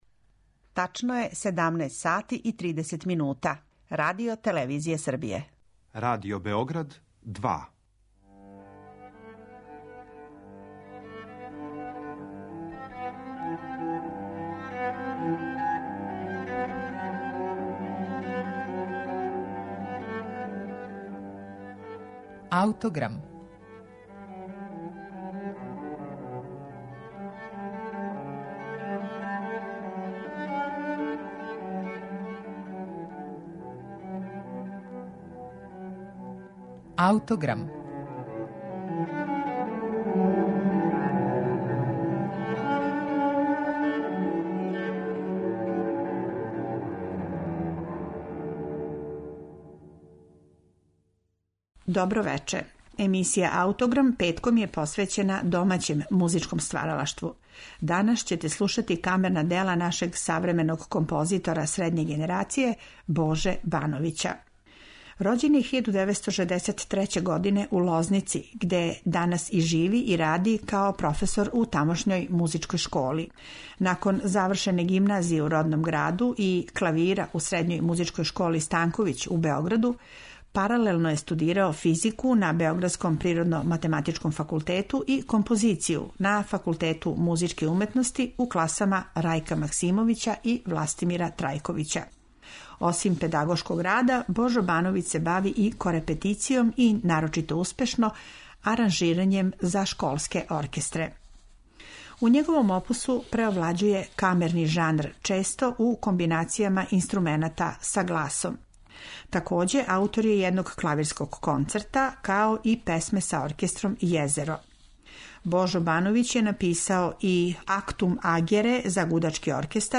Овога пута у жижи ће бити дело нашег савременог композитора средње генерације Боже Бановића ‒ „Четири краја краљевства" за флауту и гитару.